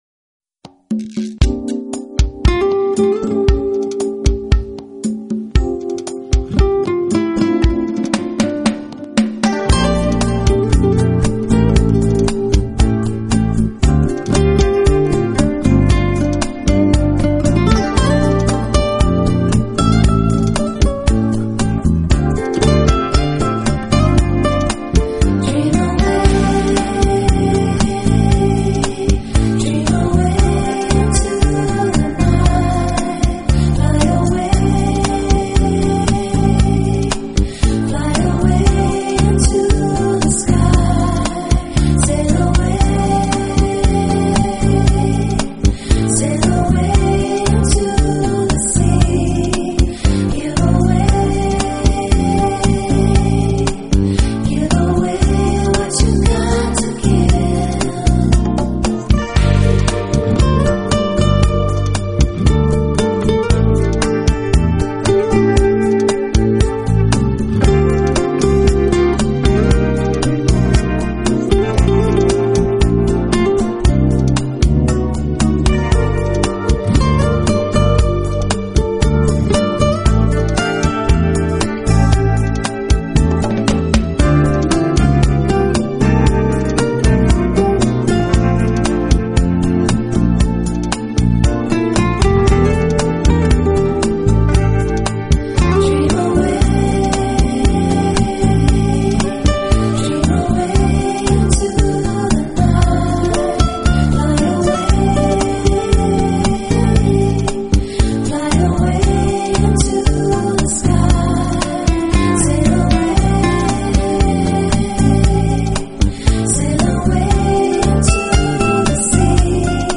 Genre: Smooth Jazz